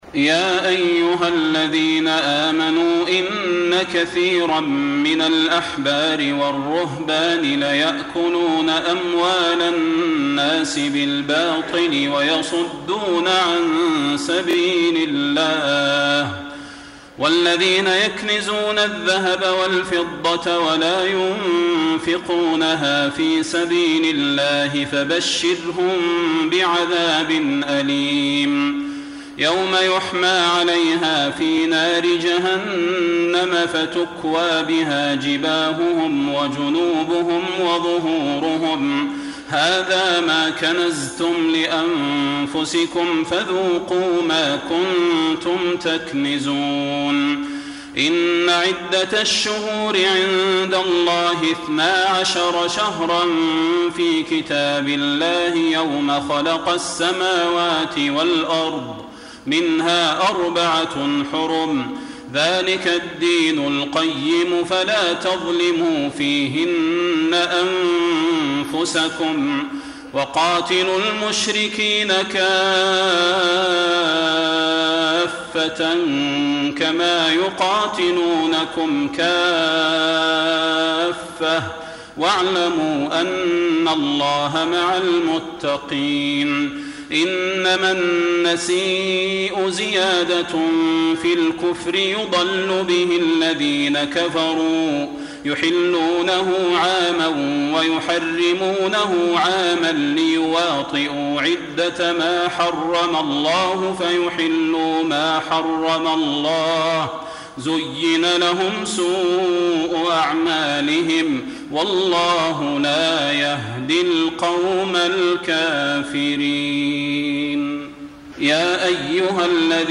تراويح ليلة 30 رمضان 1433هـ من سورة التوبة (34-93) Taraweeh 30 st night Ramadan 1433H from Surah At-Tawba > تراويح الحرم النبوي عام 1433 🕌 > التراويح - تلاوات الحرمين